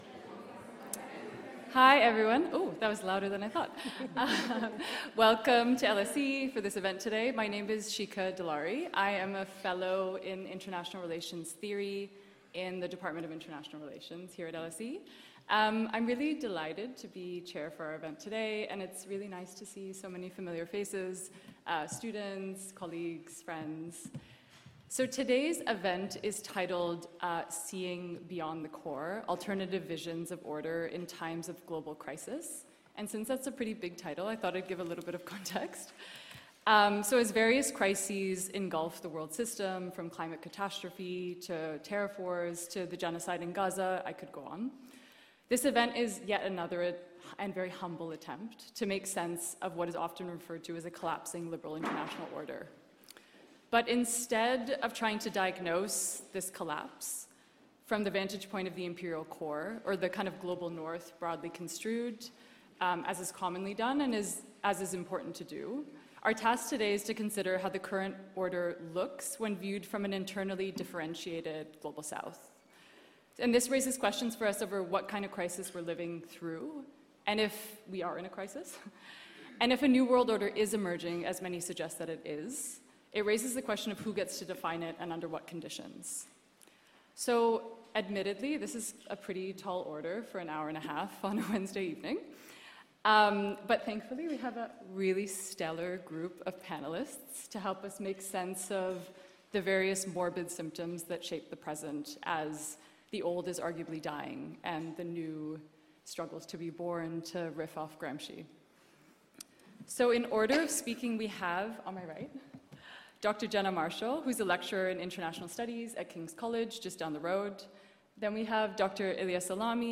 Free public event at LSE